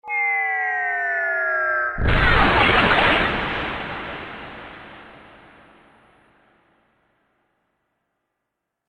جلوه های صوتی
دانلود صدای بمب 8 از ساعد نیوز با لینک مستقیم و کیفیت بالا